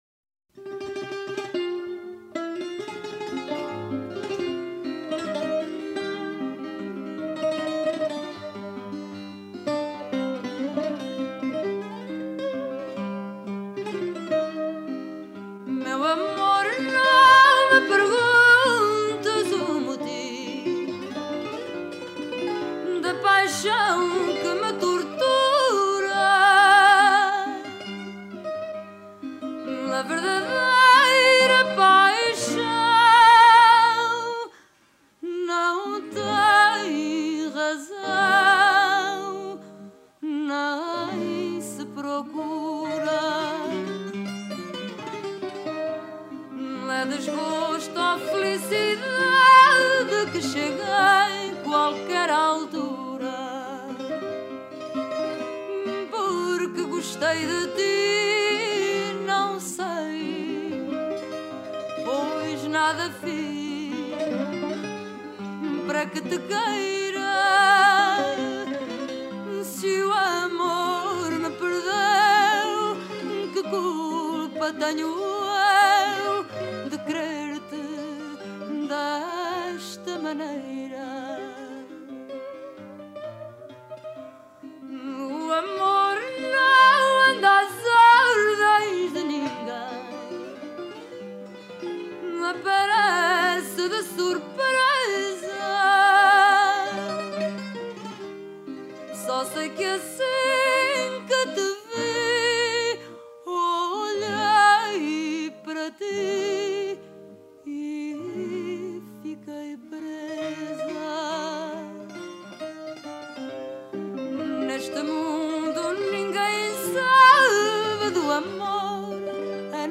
Celle-ci est plus lente.
chant
guitare portugaise
from → Fado, Fado castiço / Fado traditionnel